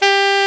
SAX A.MF G0B.wav